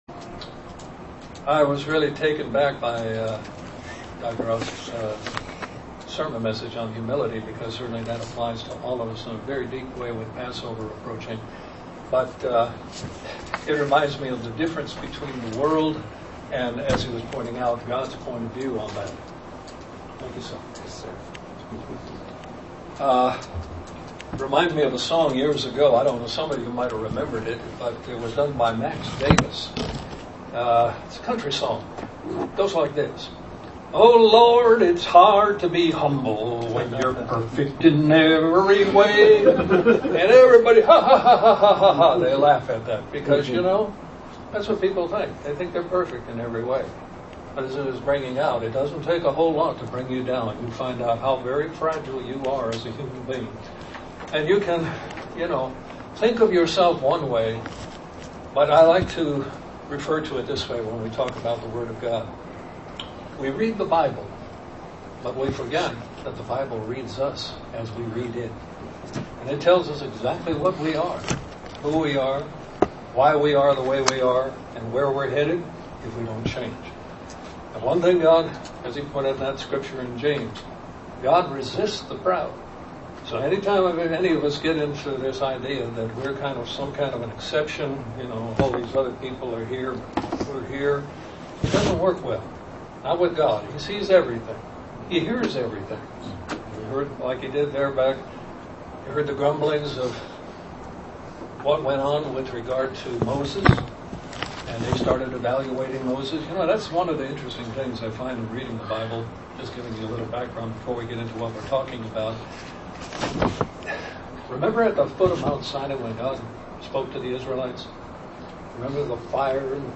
Given in Buford, GA
UCG Sermon Studying the bible?